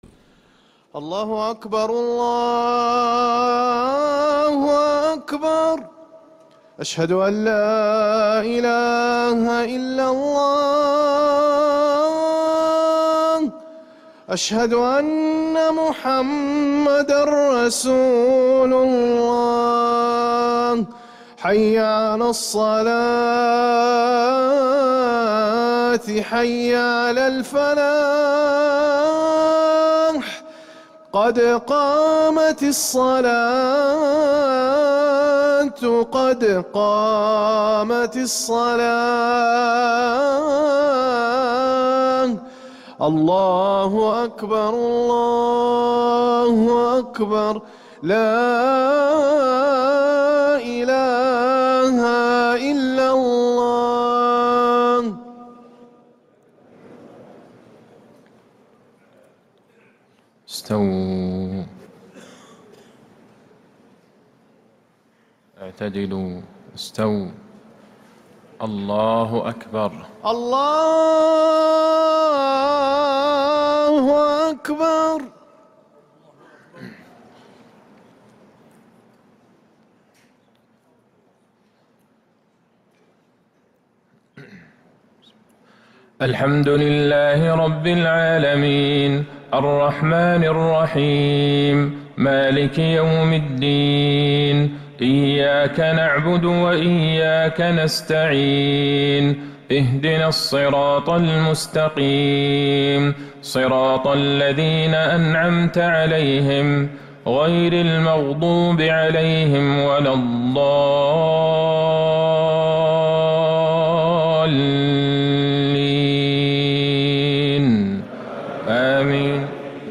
Haramain Salaah Recordings: Madeenah Isha - 13th April 2026